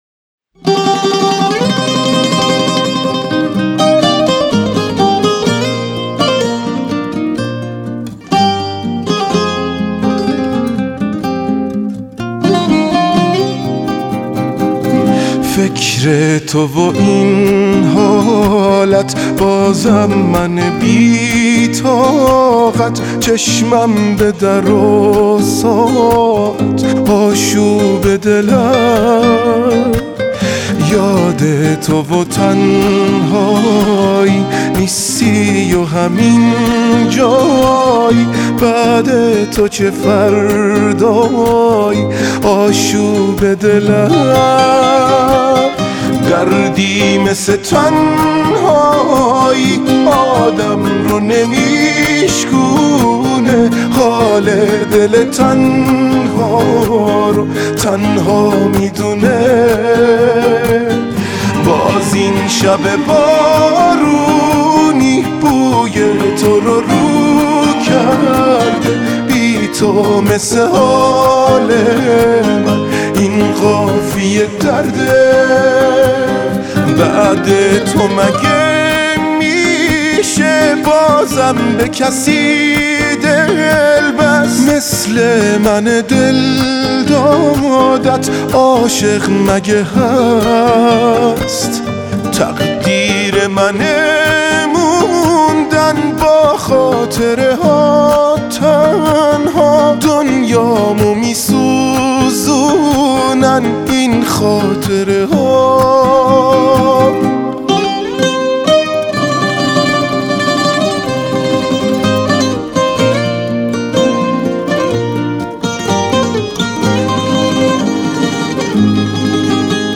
موزیک غمگین